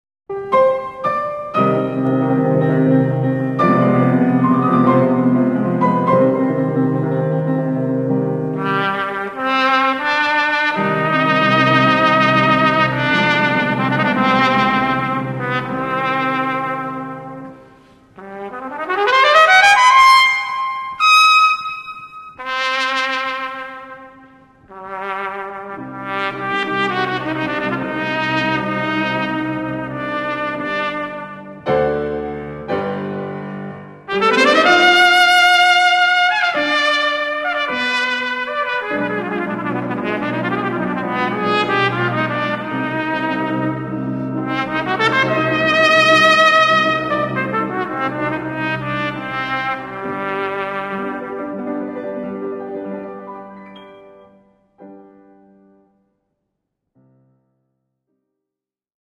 Gattung: Trompete & Klavier